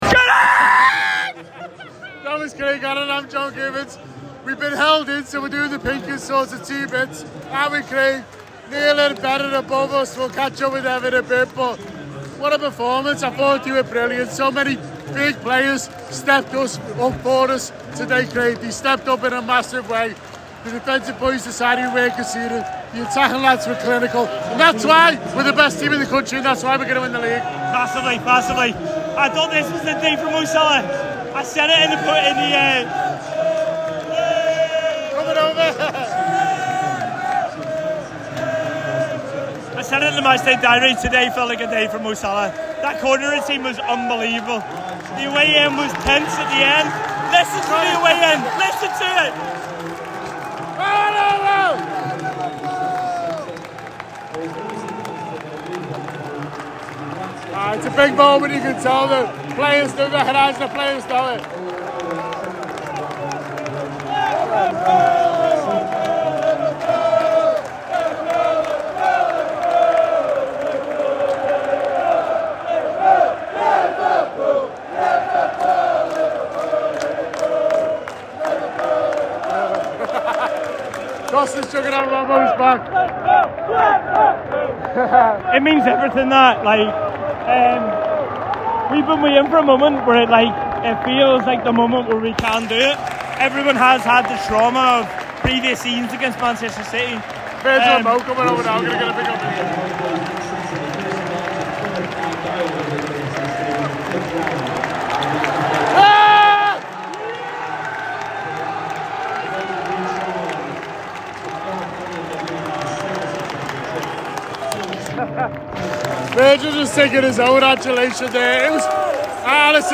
The Anfield Wrap’s post-match reaction podcast after Manchester City 0 Liverpool 2 at The Etihad.